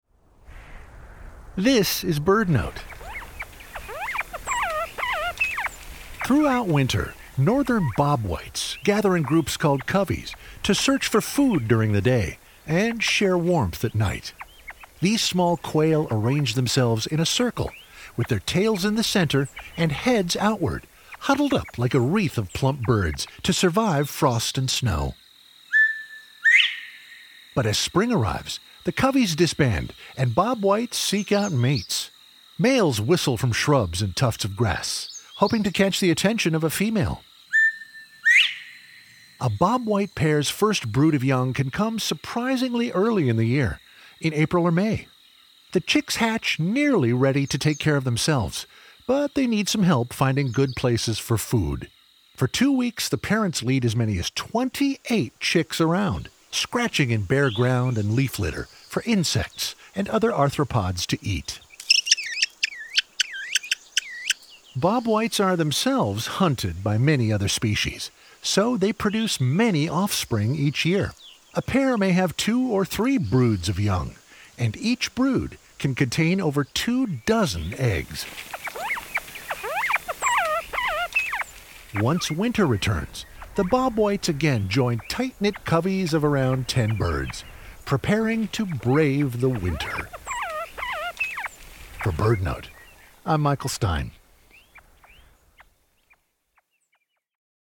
But as spring arrives, the coveys disband and bobwhites seek out mates. Males whistle from shrubs and tufts of grass, hoping to catch the attention of a female.
BirdNote is sponsored locally by Chirp Nature Center and airs live everyday at 4 p.m. on KBHR 93.3 FM.